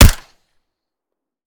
Home gmod sound weapons augolf
weap_augolf_sup_fire_plr_9mm_01.ogg